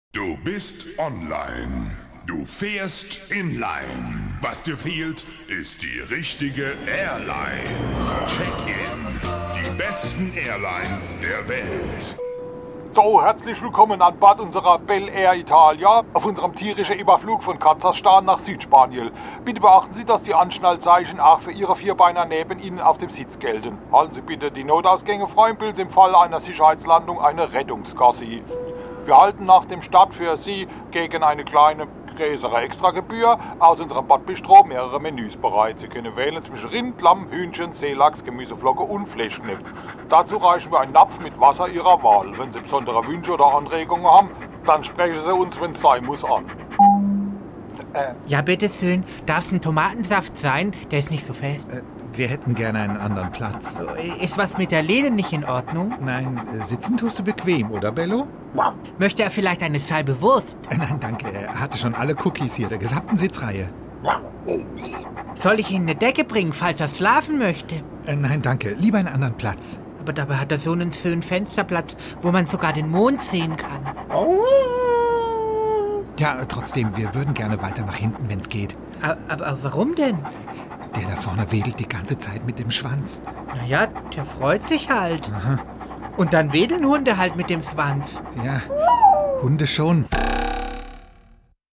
SWR3 Comedy Die BellAir-Italia